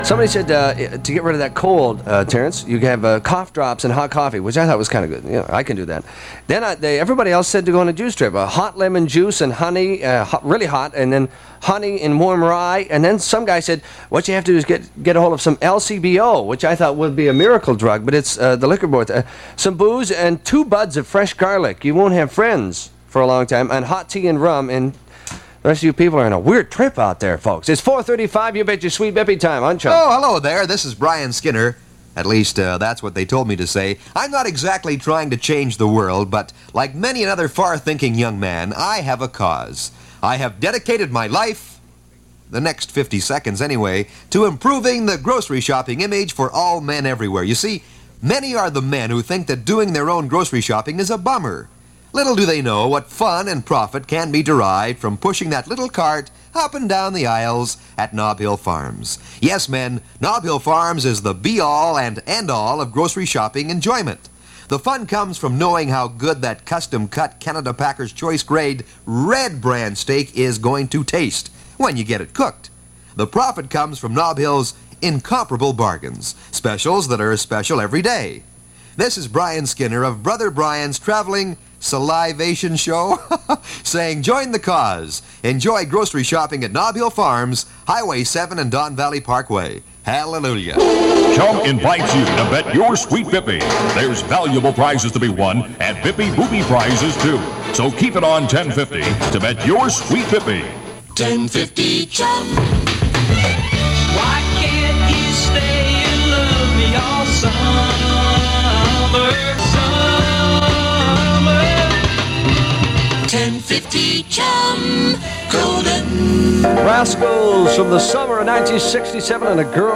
AIRCHECK OF THE WEEK
Enjoy this rare aircheck of Terry David Mulligan (SCOPED) on CHUM